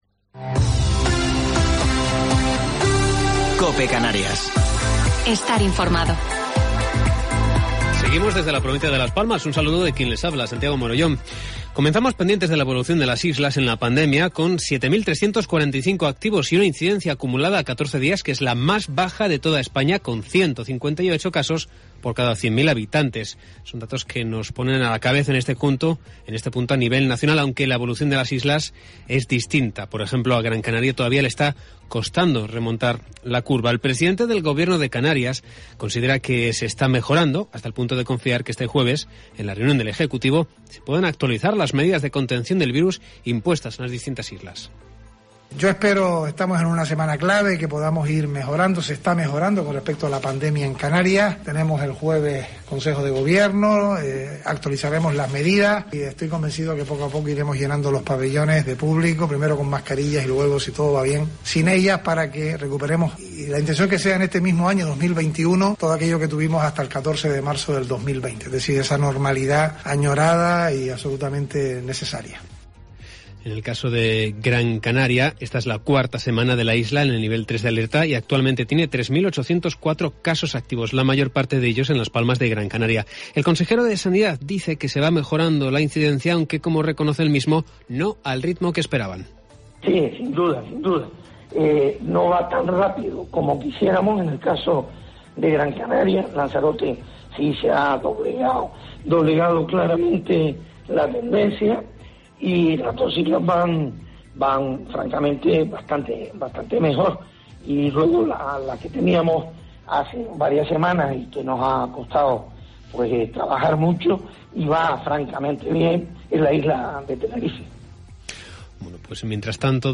Informativo local 8 de Febrero del 2021